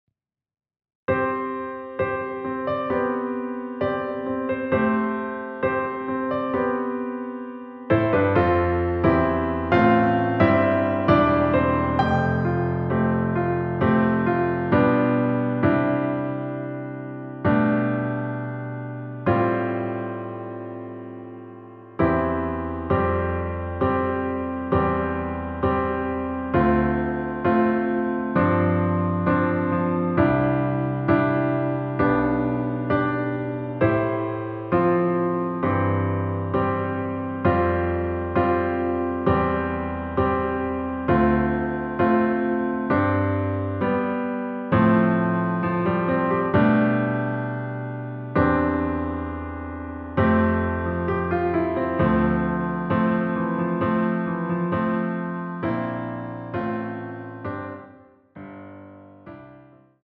반주가 피아노 하나만으로 되어 있습니다.(미리듣기 확인)
원키에서(-1)내린 피아노 버전 MR입니다.